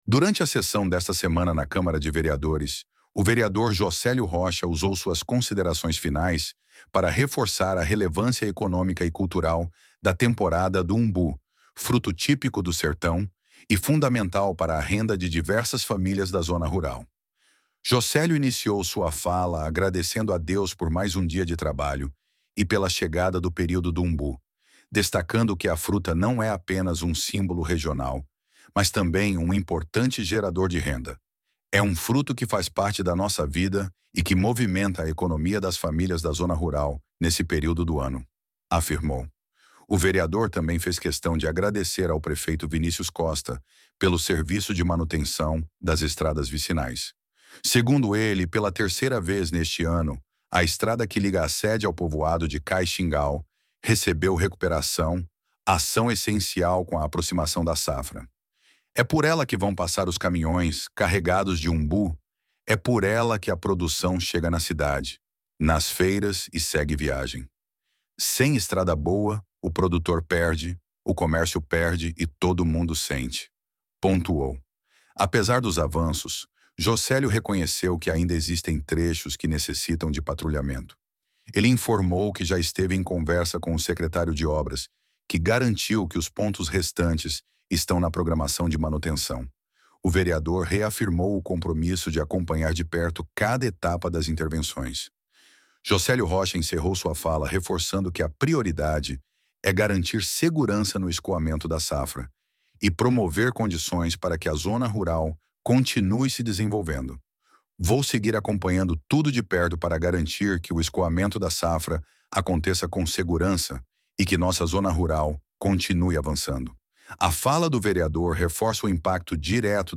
Durante a sessão desta semana na Câmara de Vereadores, o vereador Jocélio Rocha usou suas considerações finais para reforçar a relevância econômica e cultural da temporada do umbu, fruto típico do sertão e fundamental para a renda de diversas famílias da zona rural.